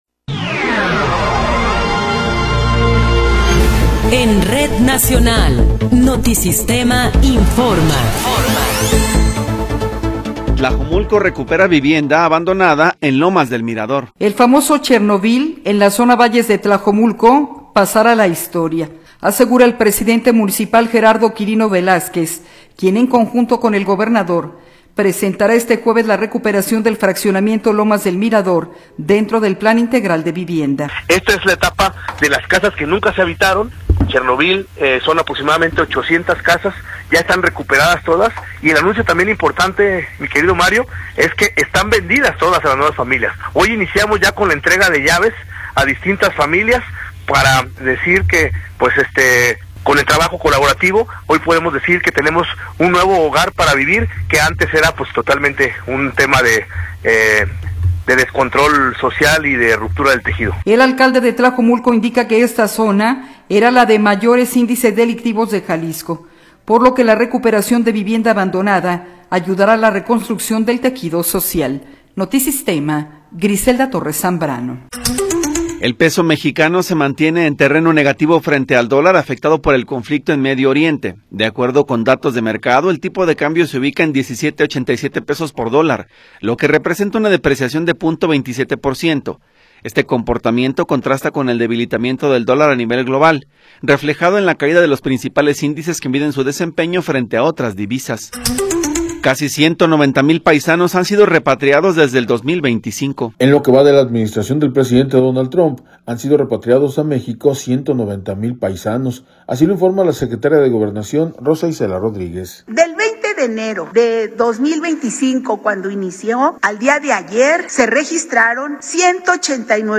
Noticiero 11 hrs. – 19 de Marzo de 2026
Resumen informativo Notisistema, la mejor y más completa información cada hora en la hora.